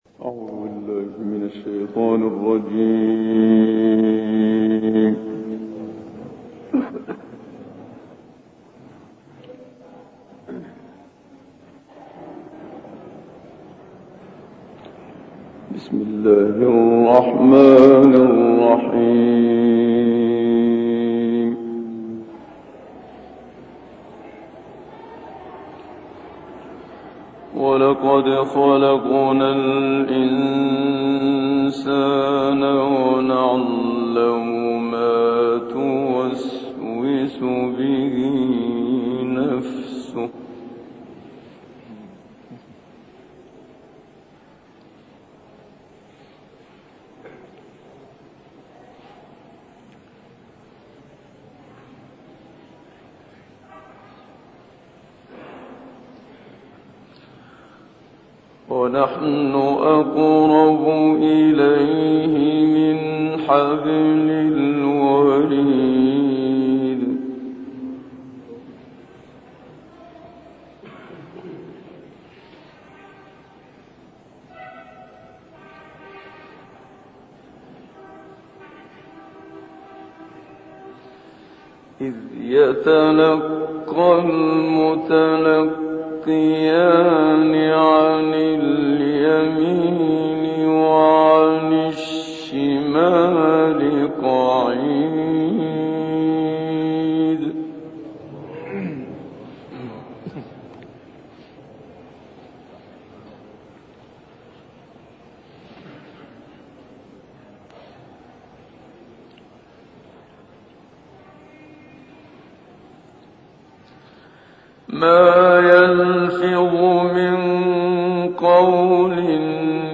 تلاوت سور مبارکه «قاف و الرحمن» با صوت «منشاوی»
این تلاوت در سال 1966 میلادی در کویت اجرا شده است و مدت زمان آن 35 دقیقه است.